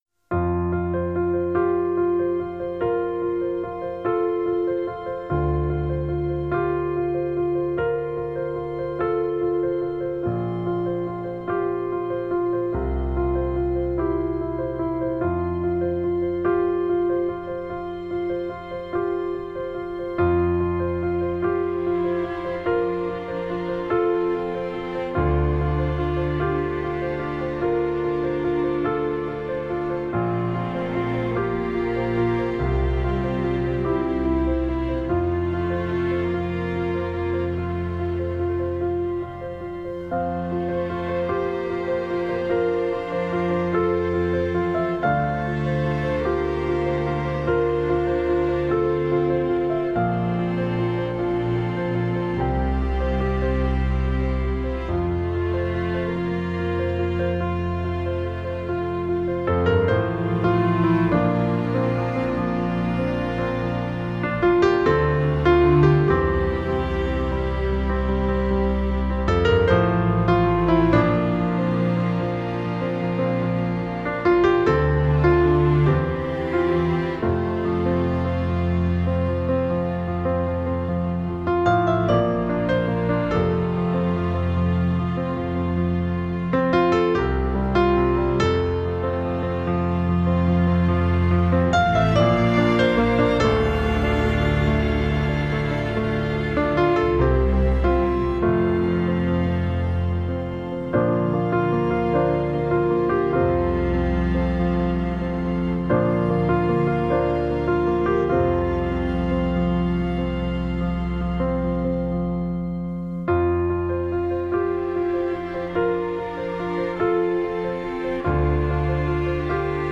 Just sharing a quiet meditation from my day